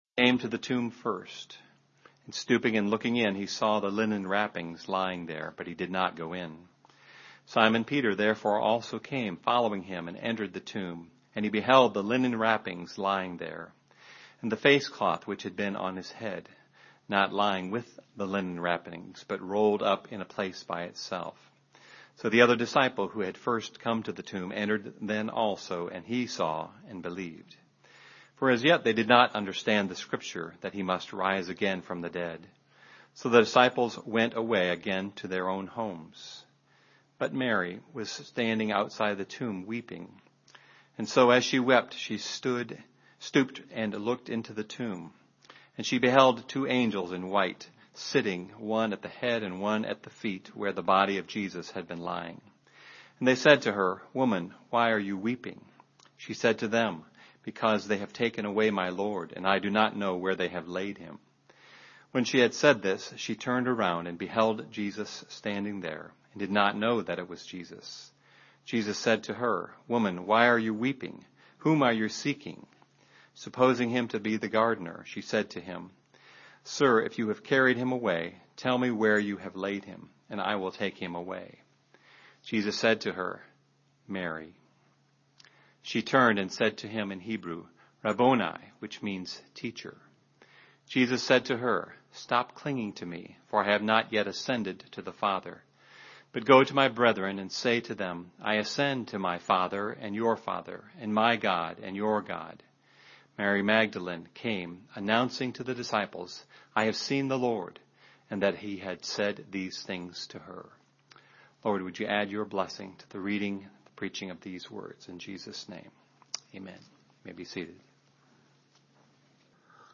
Categories: 2014, Easter, John, Sermons, The Life and Ministry of Jesus